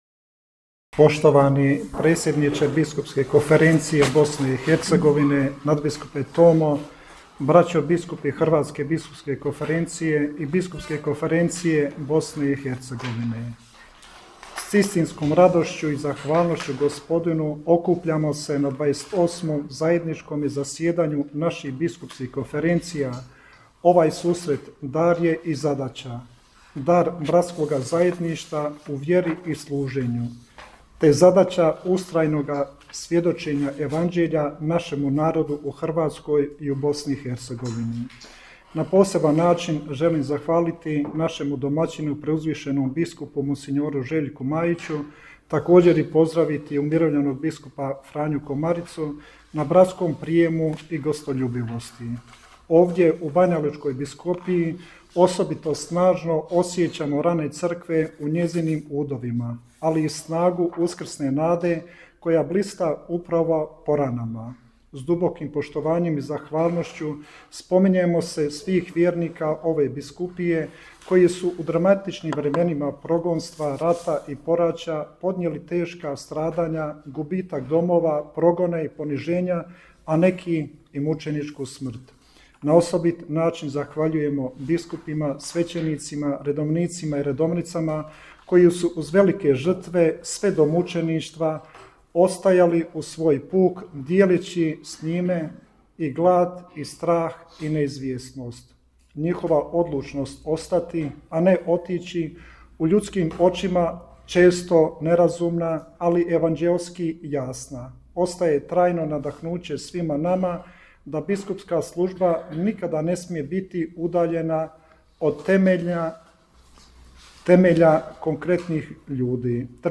U Banjoj Luci započelo zajedničko zasjedanje biskupa BK BiH i HBK
Na samom početku zasjedanja pozdravne govore uputili su predsjednik BK BiH, vrhbosanski nadbiskup mons. Tomo Vukšić i predsjednik HBK, zagrebački nadbiskup mons. Dražen Kutleša.